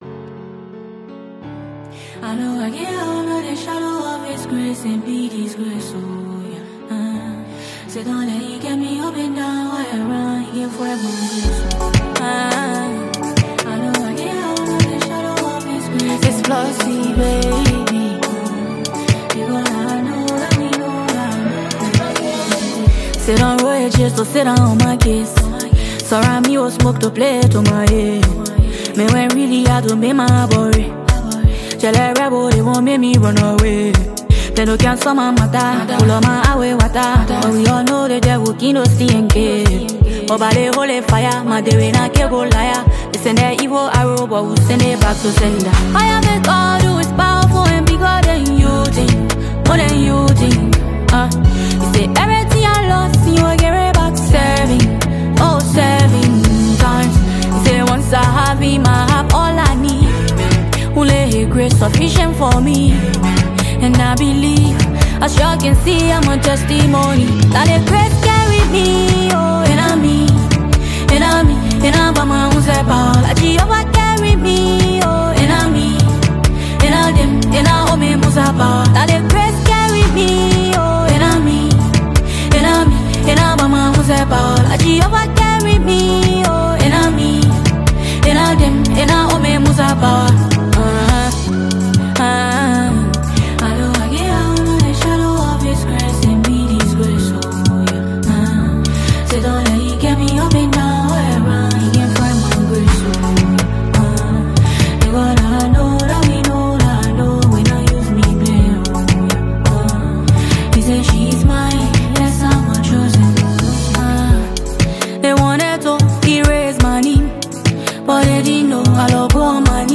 Liberian songstress